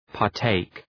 {pɑ:r’teık}
partake.mp3